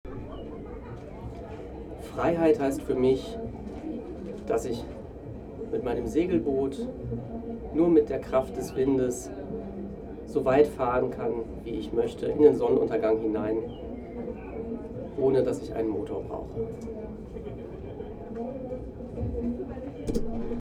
Ein Fest für die Demokratie @ Bundeskanzleramt, Berlin